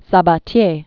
(sä-bä-tyā), Paul 1854-1941.